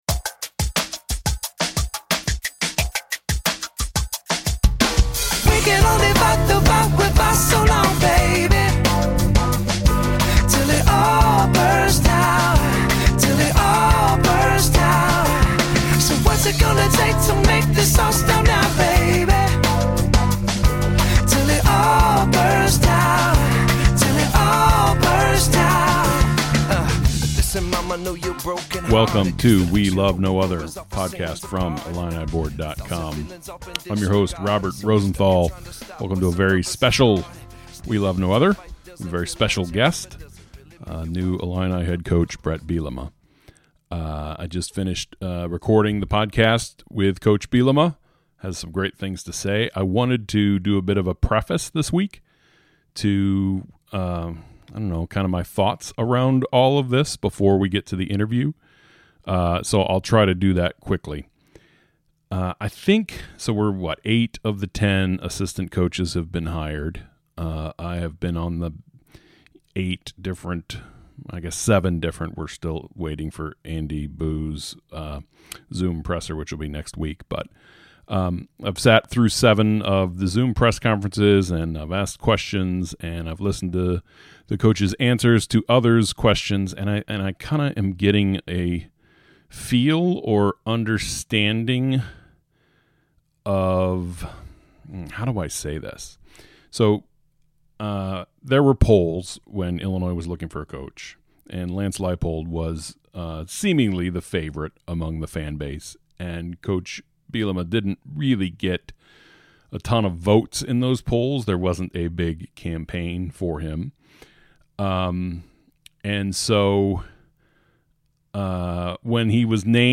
The Bielema interview begins at 11:52.